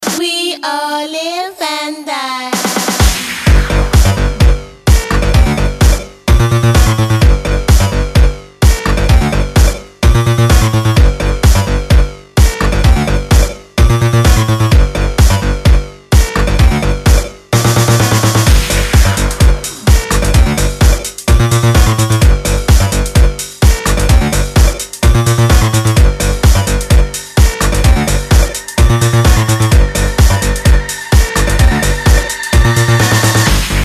клубные